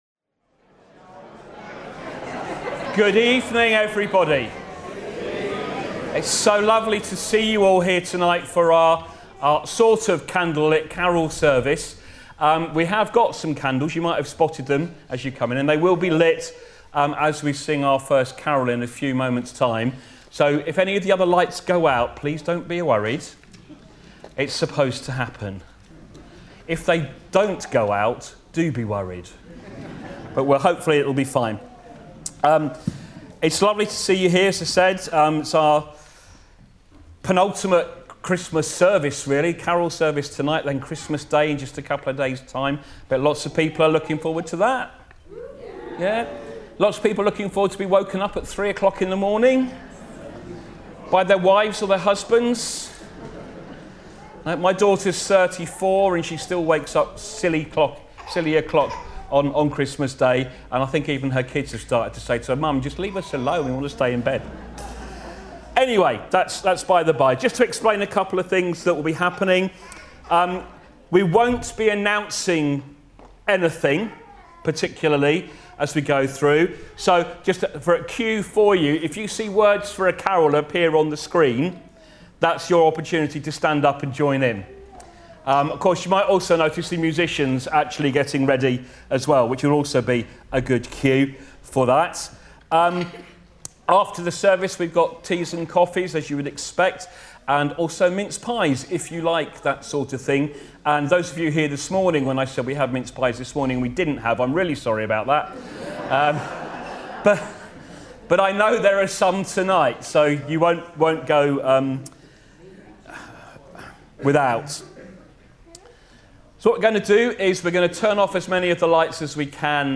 Join us for our evening Christmas Carol Service.
21/12/2025 21 December 2025 – Evening Carol Service Service Type: Evening Service Join us for our evening Christmas Carol Service.